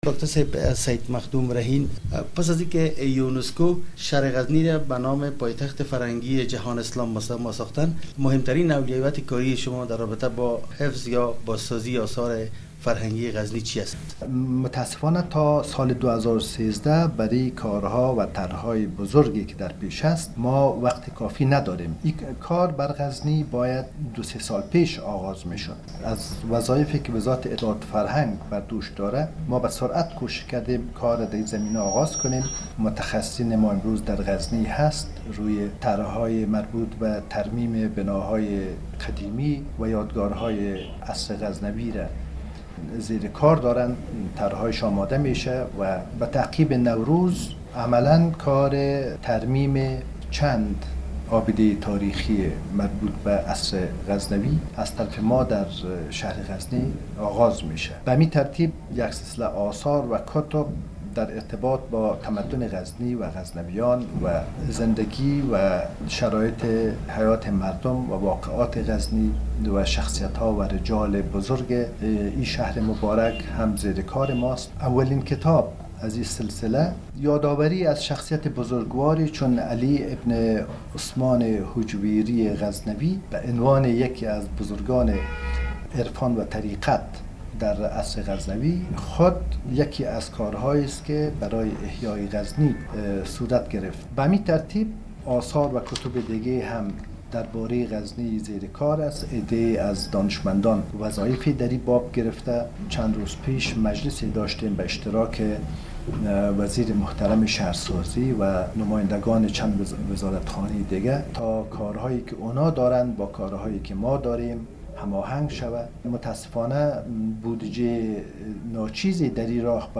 مصاحبه با سید مخدوم رهین وزیر اطلاعت وفرهنگ افغا نستان در مورد کار باز سازی آثار تاریخی در ولایت غزن